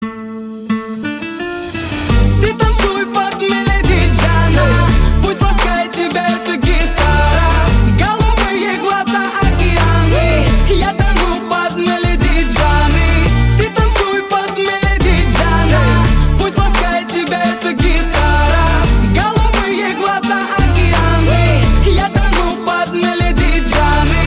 • Качество: 320, Stereo
гитара
Хип-хоп
романтичные